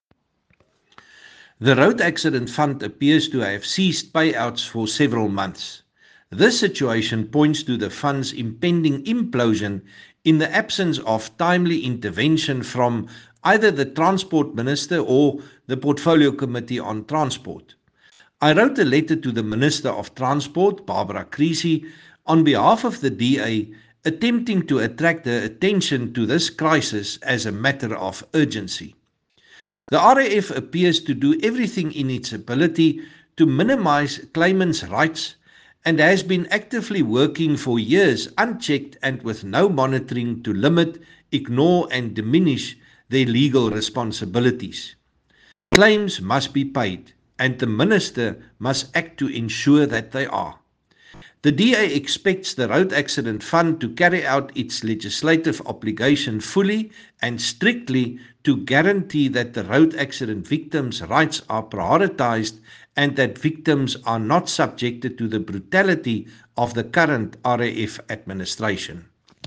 English soundbite by Dr Chris Hunsinger MP.